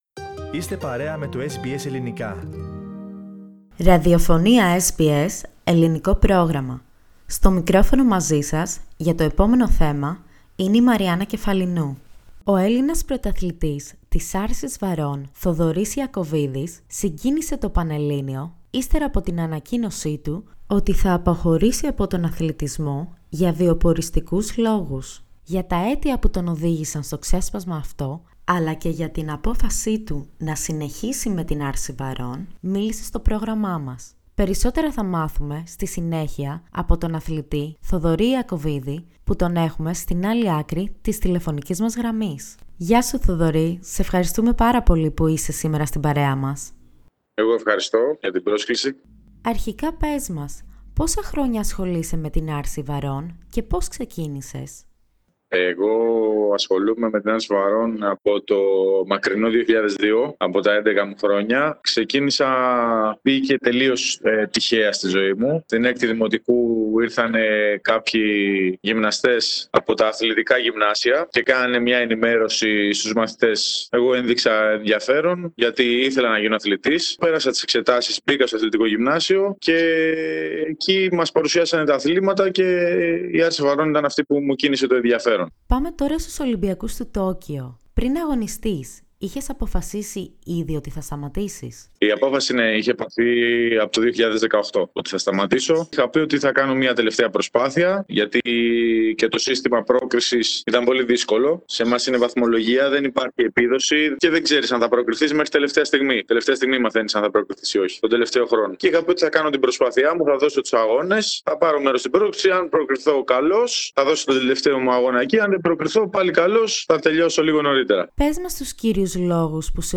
Για τα αίτια που τον οδήγησαν στην απόφασή του να συνεχίσει με την άρση βαρών και να μην επιμείνει στην δήλωσή του για αποχώρηση και το τί σχεδιάζει από δω και μπρος μίλησε στο SBS Greek ο Έλληνας πρωταθλητής.